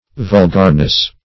Vulgarness \Vul"gar*ness\, n.